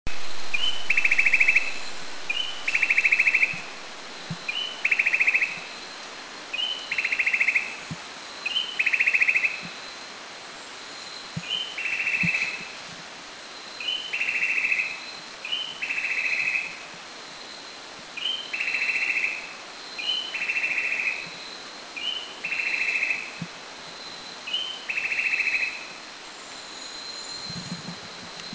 Grey-backed Tailorbird  Orthotomus derbianus  Country endemic
B2A_Grey-backedTailorbird1Makiling210_SDW.mp3